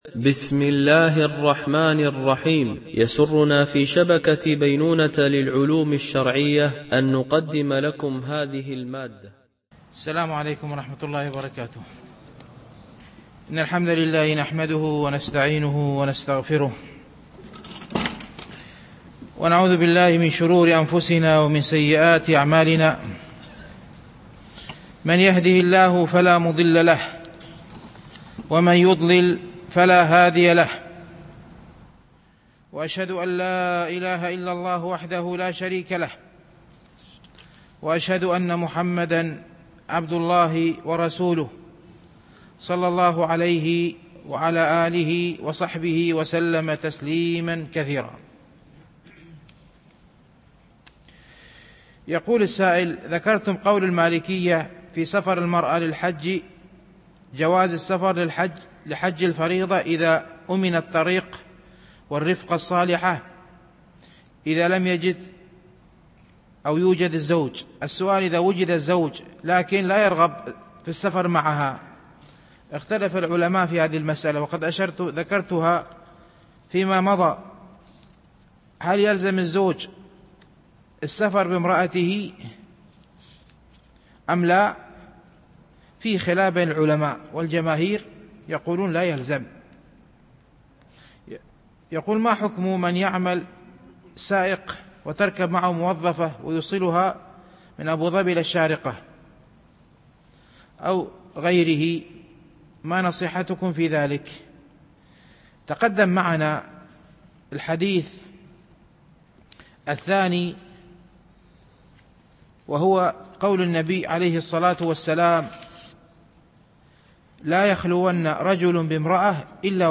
شرح رياض الصالحين – الدرس 258 ( الحديث 997 – 999 )